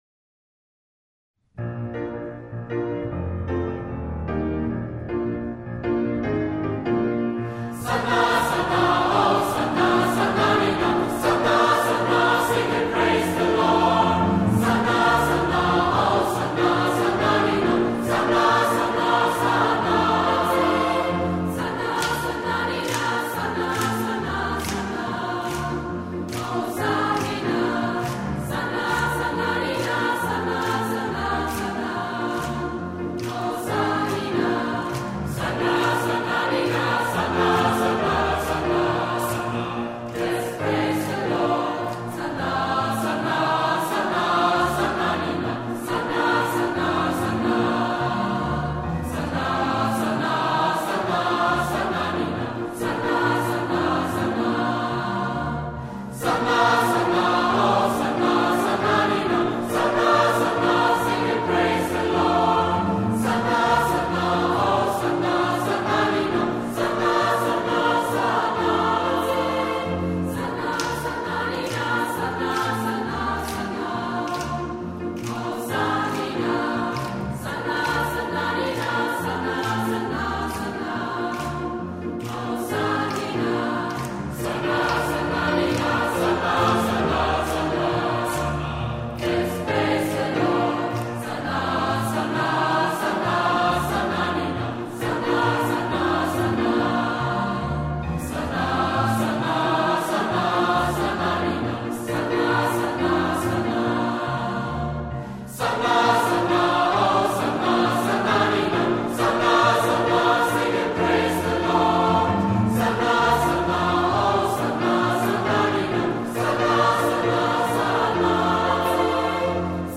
Chor, Solisten und Orchester
in der Entenfußhalle des Klosters Maulbronn
Gospels und Spirituals: